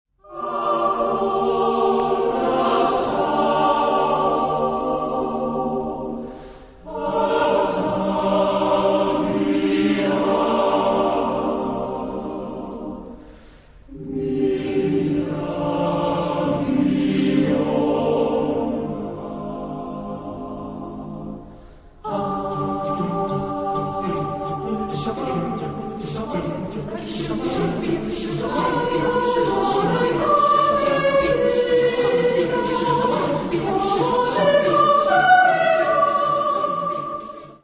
Genre-Style-Forme : Profane ; Populaire ; Latino-américain
Caractère de la pièce : contrasté
Type de choeur : SATBB  (5 voix mixtes )
Tonalité : do majeur